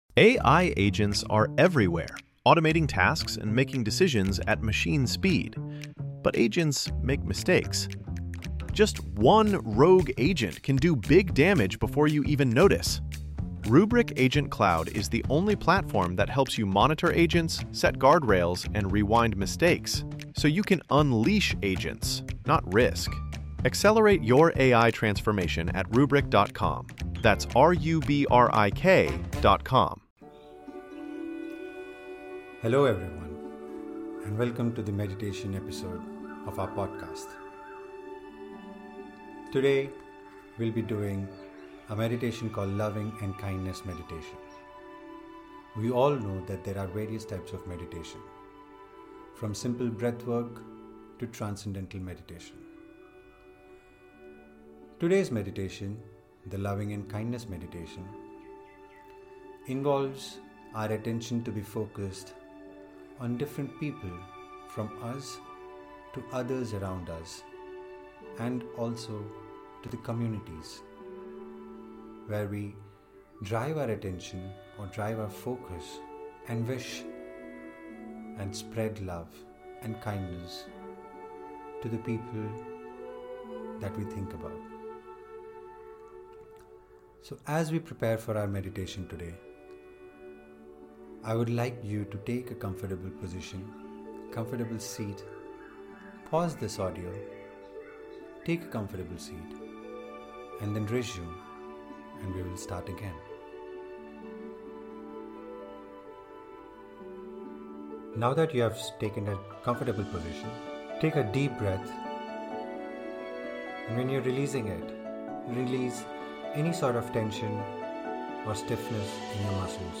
A guided meditation for destressing from anywhere you are- Special episode.